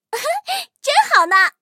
M5斯图亚特获得资源语音.OGG